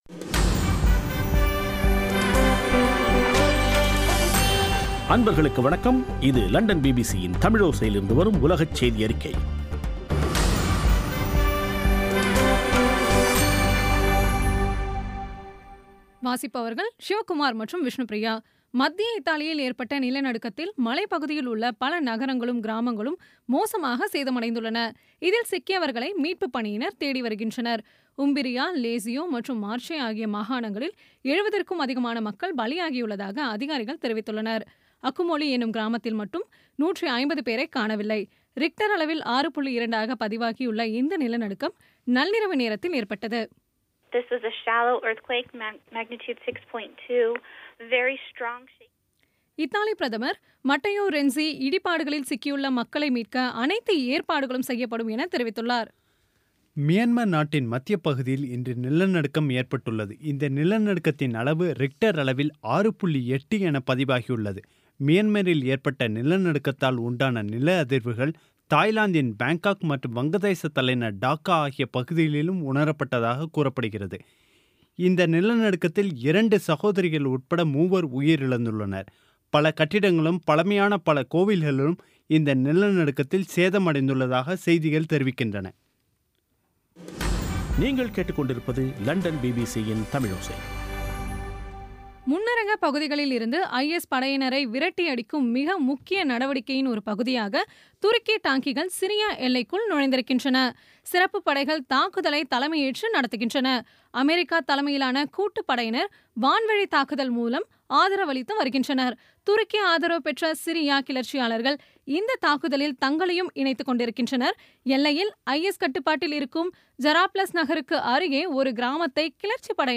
பி பி சி தமிழோசை செய்தியறிக்கை (24/08/2016)